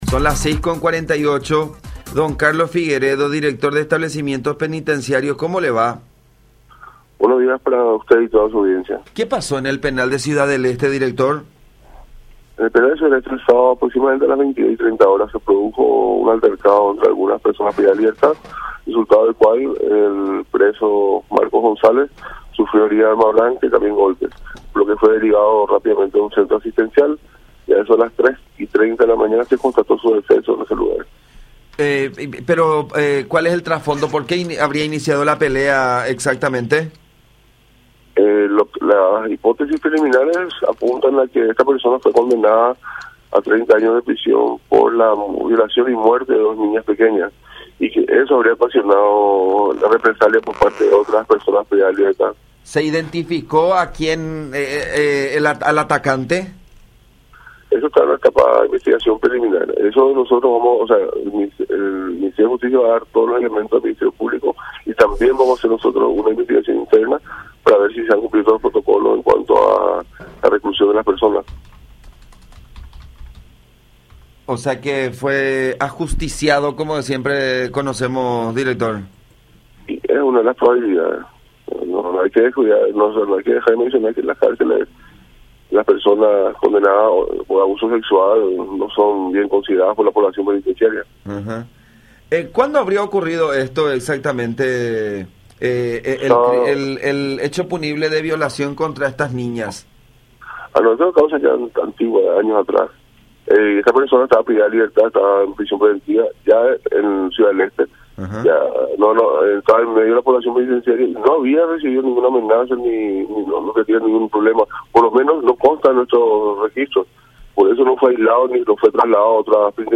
07-Carlos-Figueredo-Director-de-Establecimientos-Penitenciarios.mp3